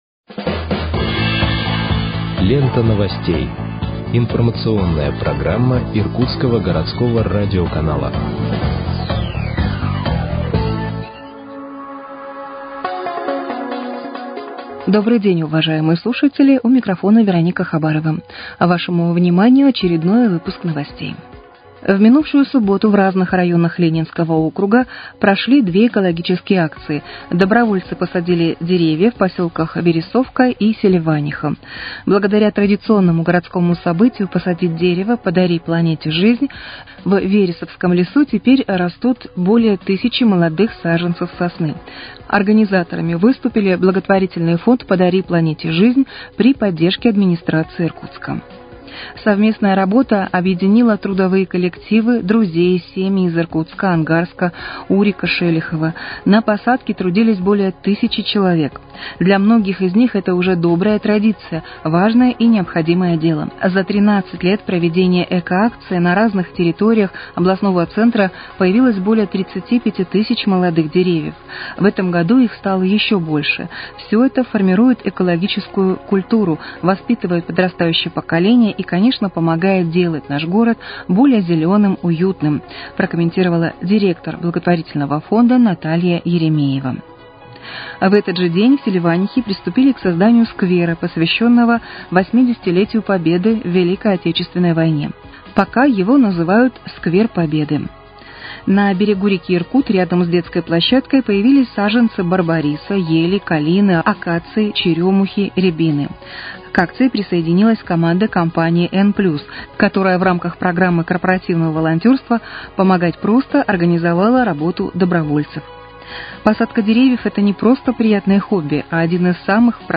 Выпуск новостей в подкастах газеты «Иркутск» от 30.05.2025 № 2